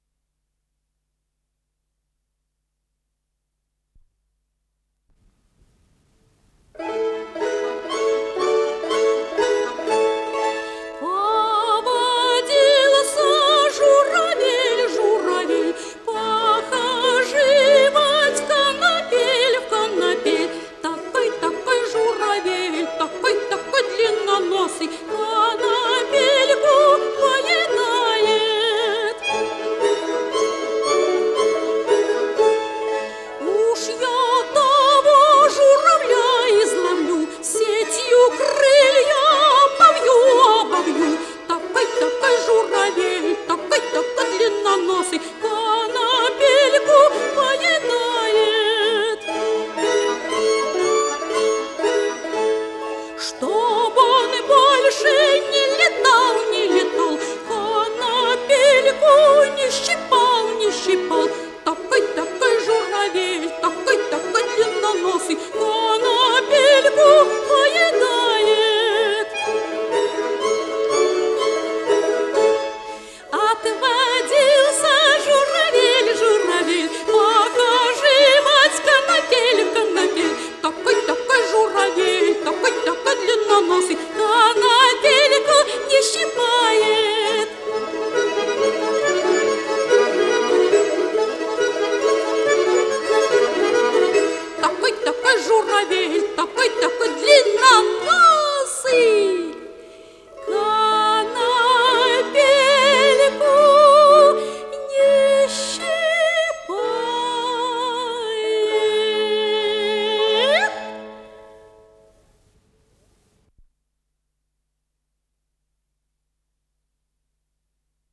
пение
гусли
баян
ВариантДубль моно